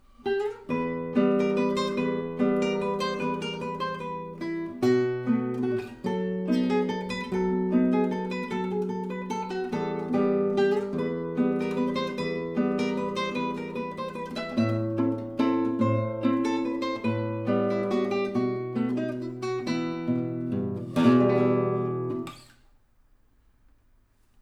I also recorded a bit of guitar in the stairwell as well, same gain (about 3/4 of the mic gain knob), mic about 1m away from the guitar.
There appears to be less reverb than what I used to remember… (probably because I have more stuff displaced around absorbing the reflections and/or because I forgot to close the doors (or perhaps I should have set the mic to omni instead of cardioid?).
Raw recording.